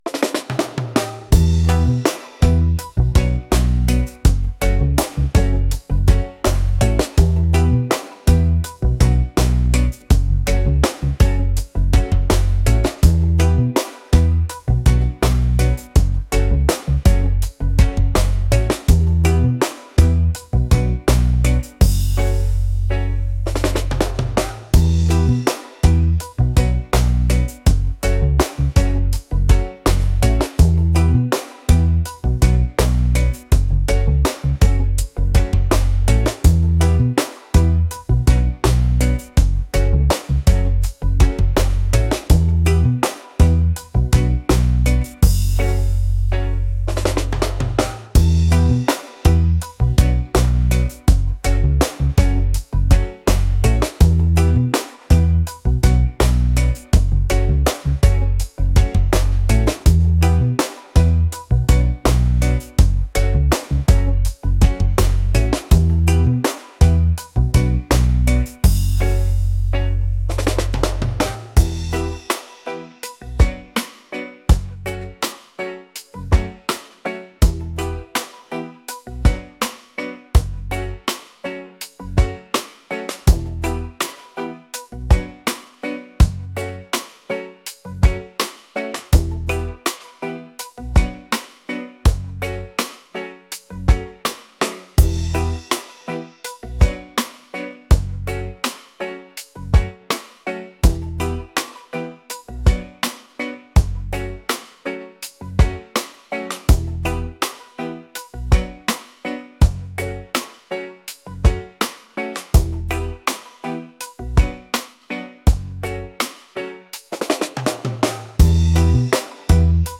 laid-back | reggae